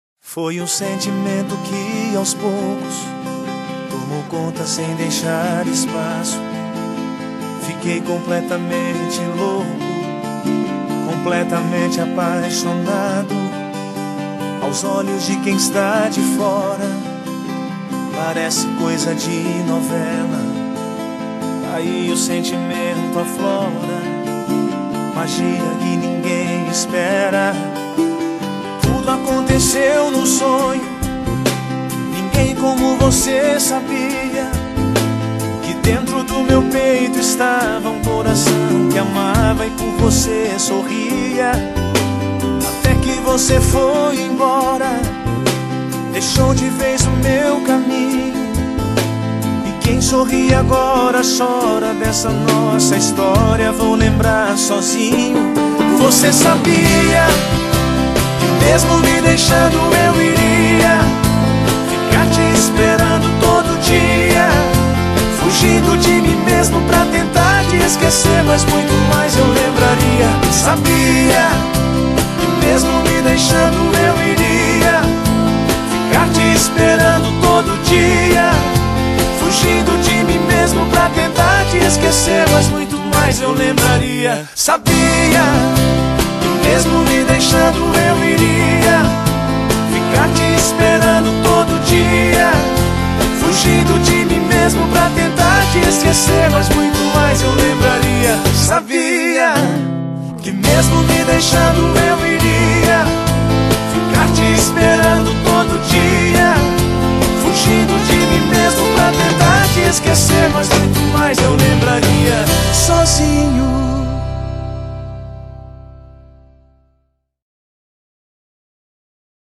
BPM137